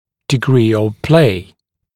[dɪ’griː əv pleɪ][ди’гри: ов плэй]степень люфта